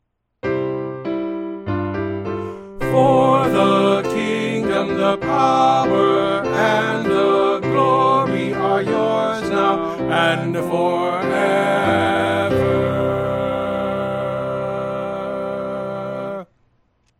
Voice | Downloadable